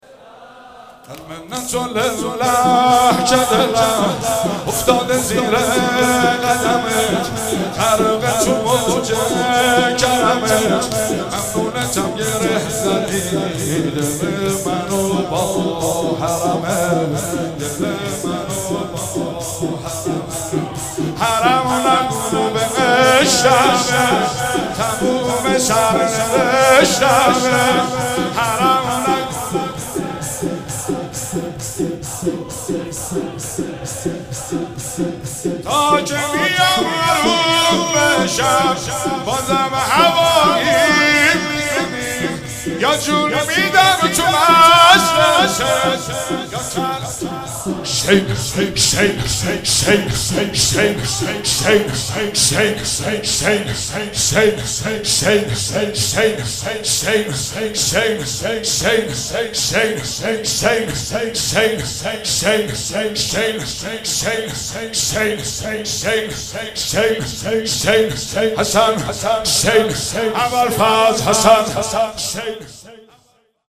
شب سوم رمضان 95، حاح محمدرضا طاهری
06 heiate eradatmandan shabe5.mp3